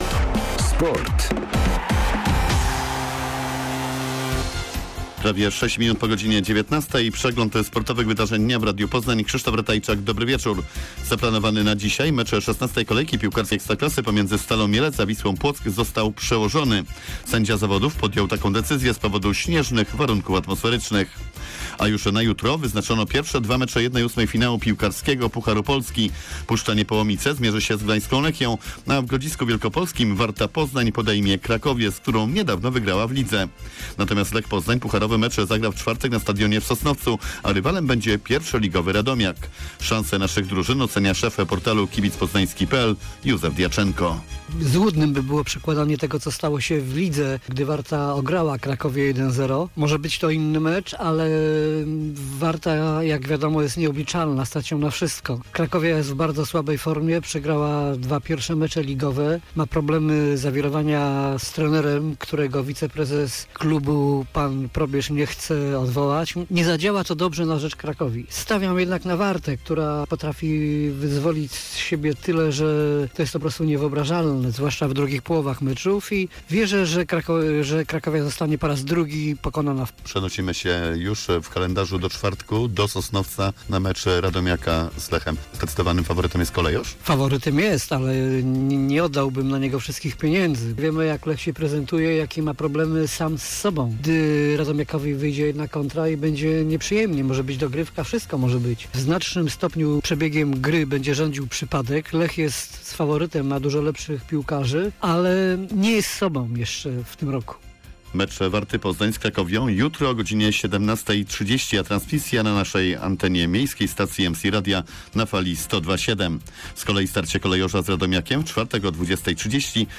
08.02.2021 SERWIS SPORTOWY GODZ. 19:05